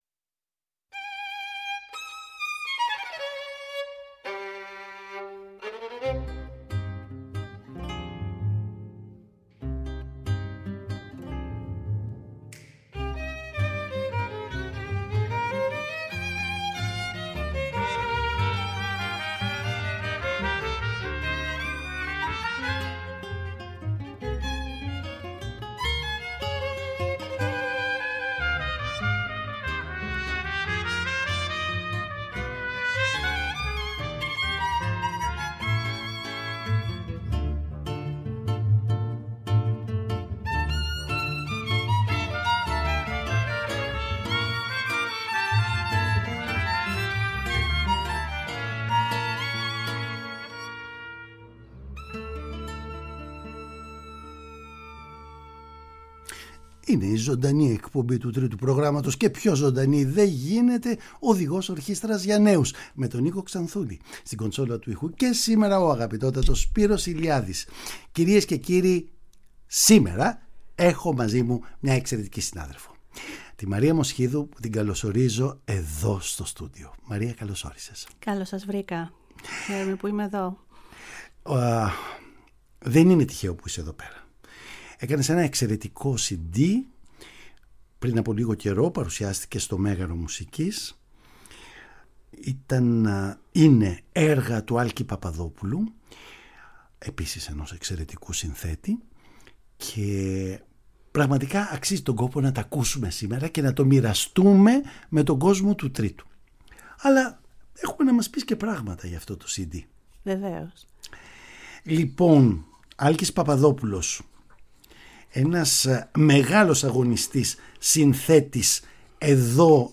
Η πρόσφατη έκδοση του CD με έργα του Άλκη Παπαδόπουλου είναι αφορμή και λόγος της πρόσκλησής της στο στούντιο του Τρίτου Προγράμματος.